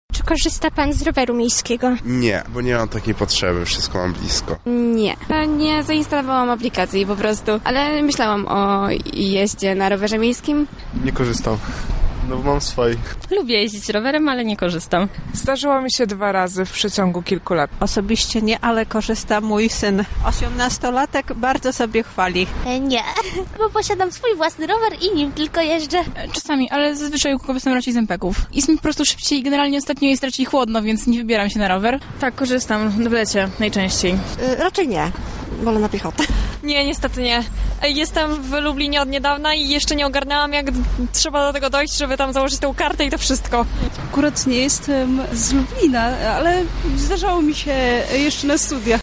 Zapytaliśmy mieszkańców Lublina czy korzystają z systemu Lubelskiego Roweru Miejskiego: